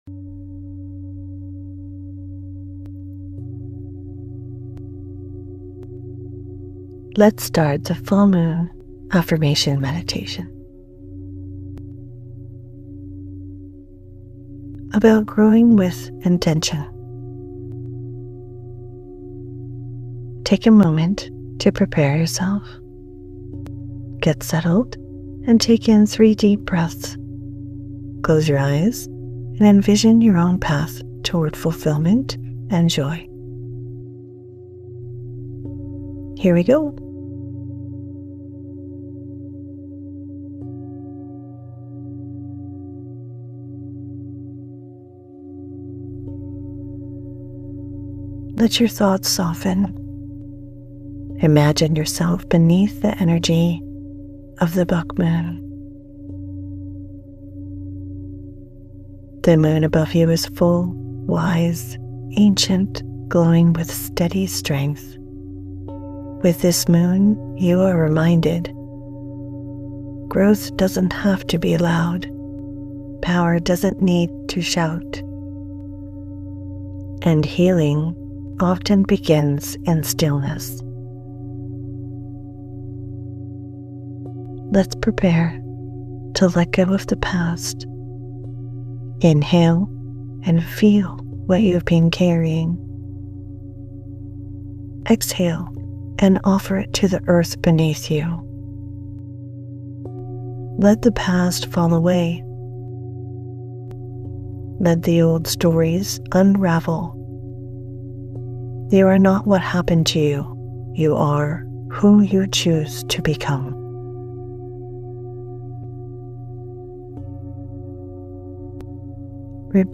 In this short affirmation meditation, we align with the steady energy of the Buck Moon to release what no longer serves us, sharpen our focus, and step forward with grounded purpose.